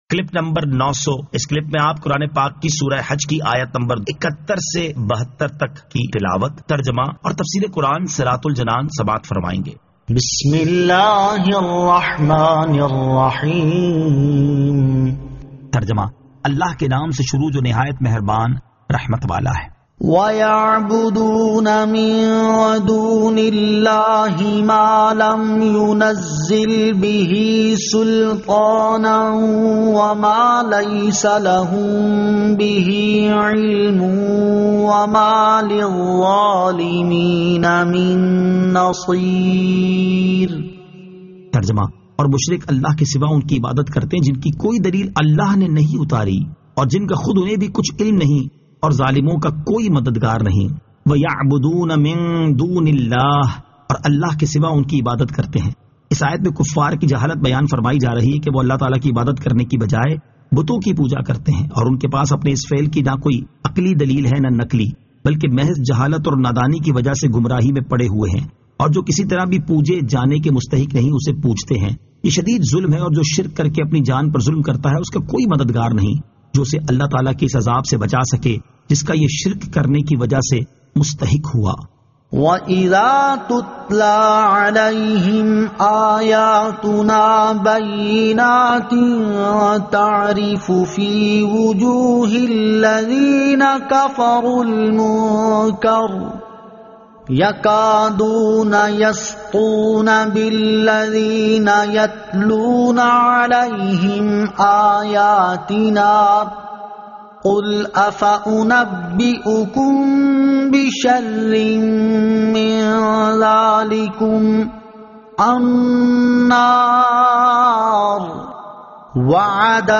Surah Al-Hajj 71 To 72 Tilawat , Tarjama , Tafseer